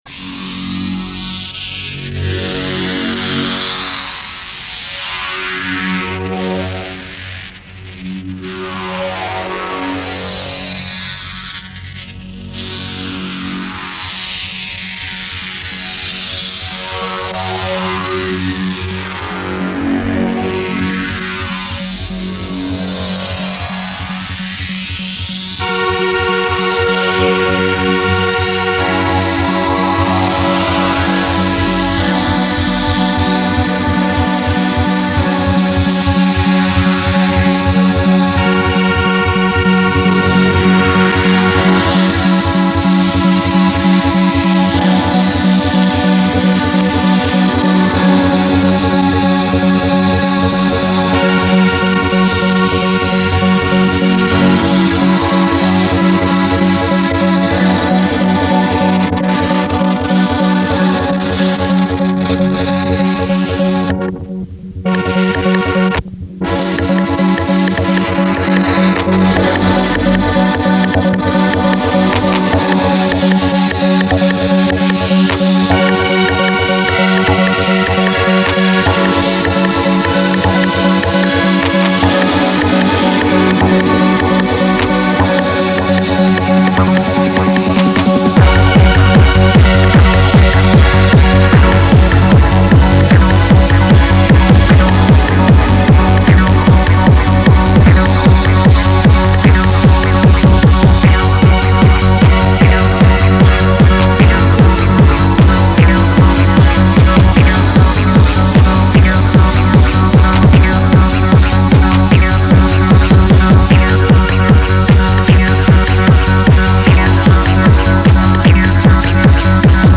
c'est un peu style bonzai, mais j'ai beau eplucher mes vieux CD je trouve pas...